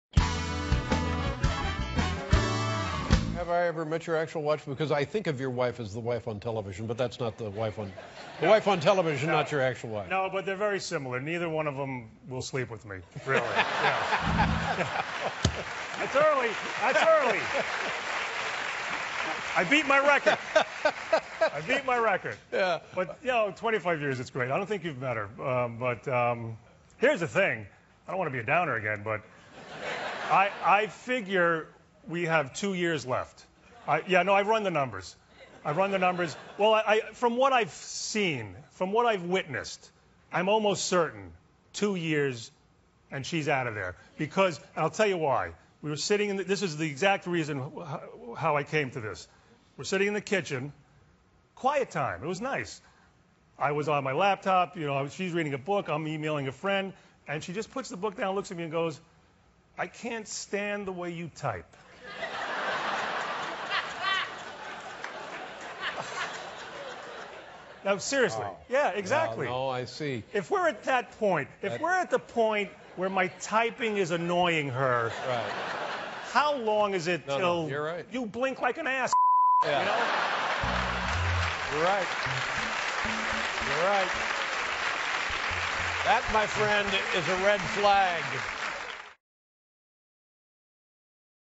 访谈录 2012-07-11&07-13 《人人都爱雷蒙德》雷·罗马诺专访 听力文件下载—在线英语听力室